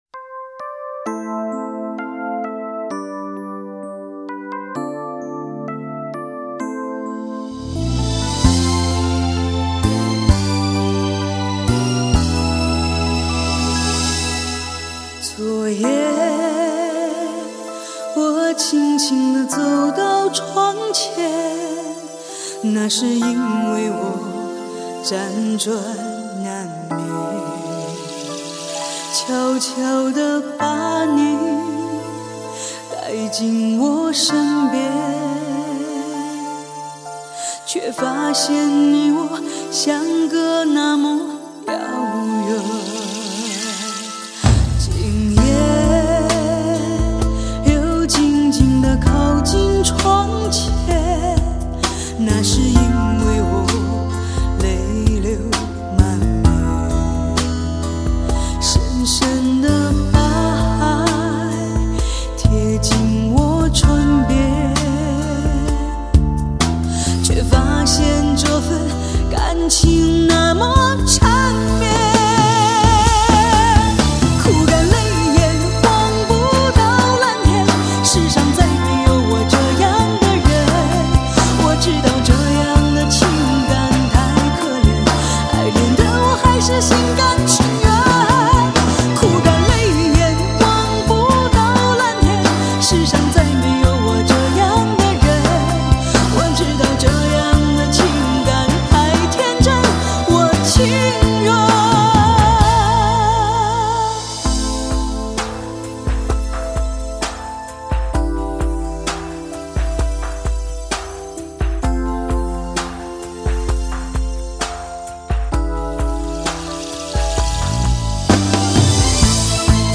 恰如她的声音，低回婉转，哀愁如雨丝分飞，悲凉并不凄凉，从容更叫人动容。